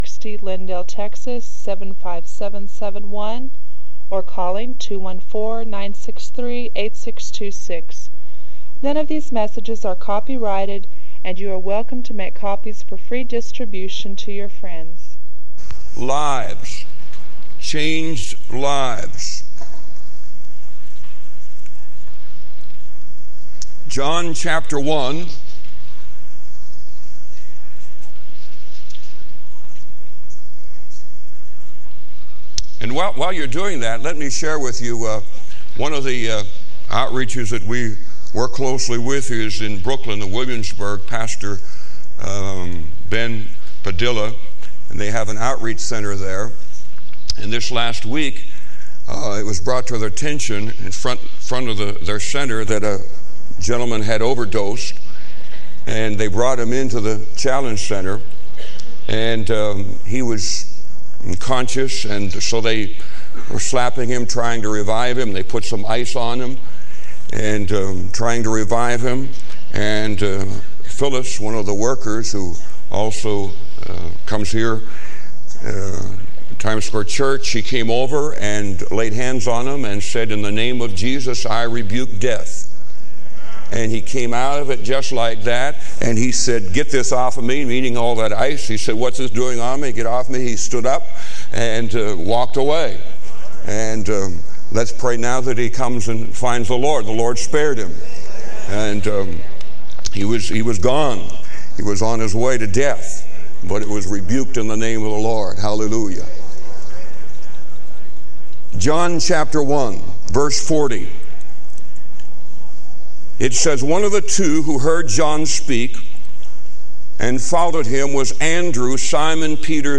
This sermon encourages listeners to embrace their God-given names and roles with faith and conviction.